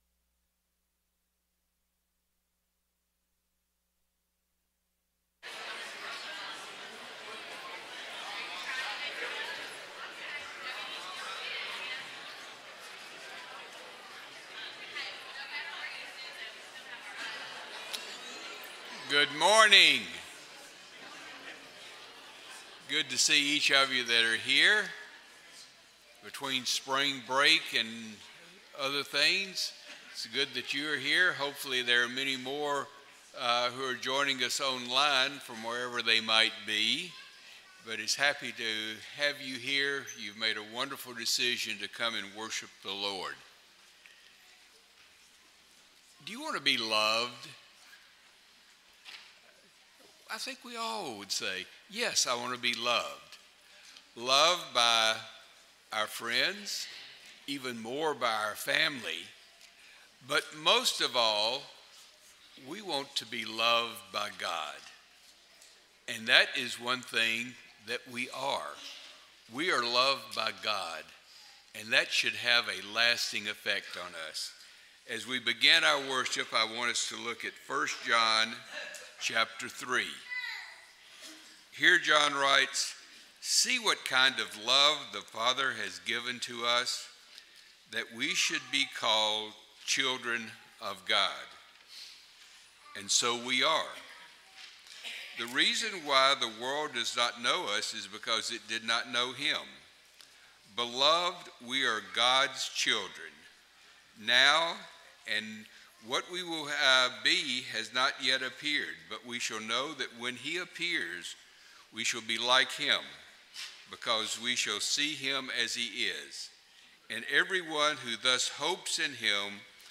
Habakkuk 2:20, English Standard Version Series: Sunday AM Service